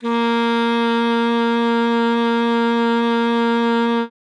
42c-sax04-a#3.wav